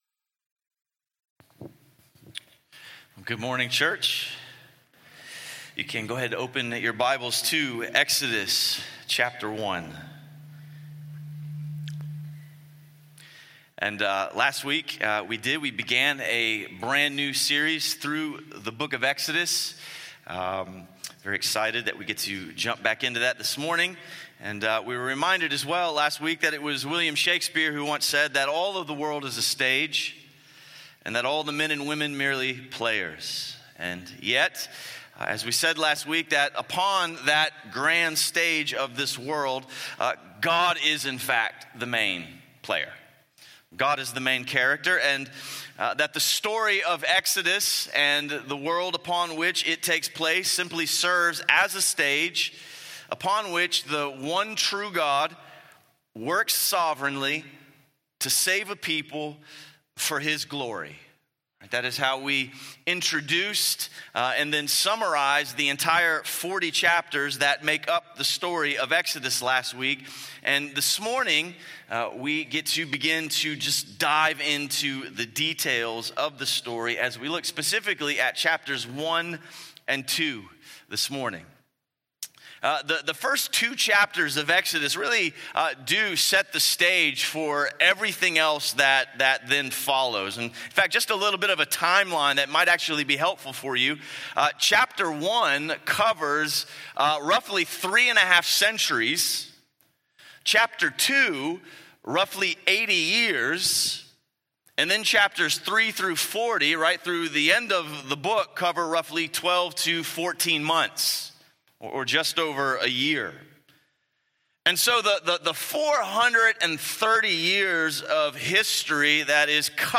Sunday Sermons – Crossway Community Church